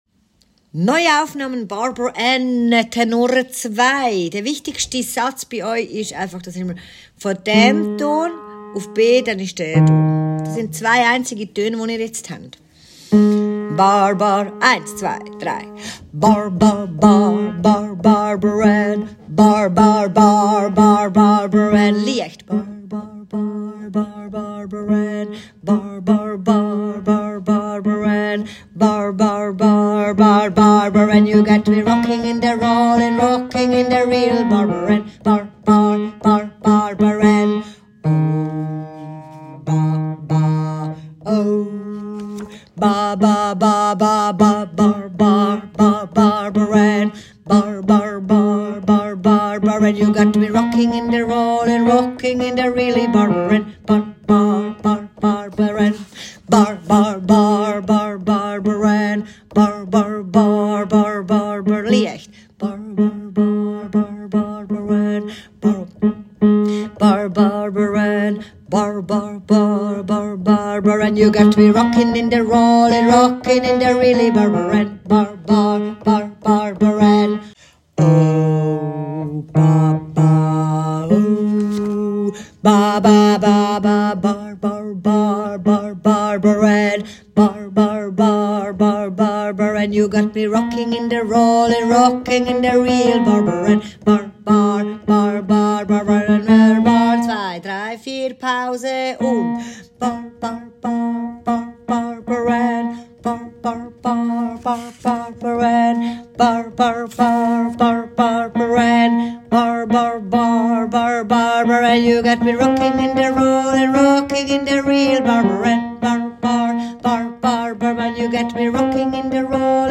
2. Tenor